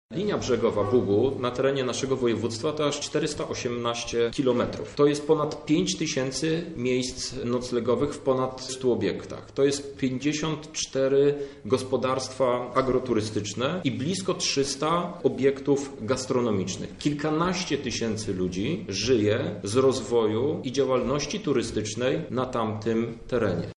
O przemyśle turystycznym w tamtym rejonie mówi europoseł Krzysztof Hetman.